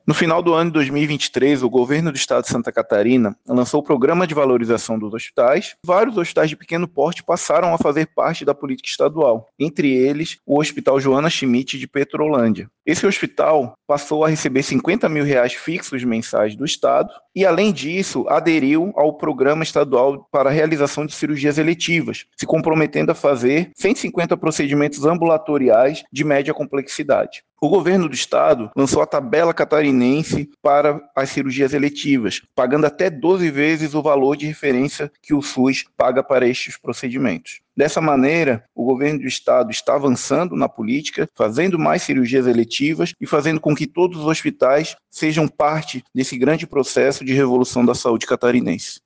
O Secretário de Estado da Saúde, Diogo Demarchi, comenta sobre o avanço na saúde para os moradores da região: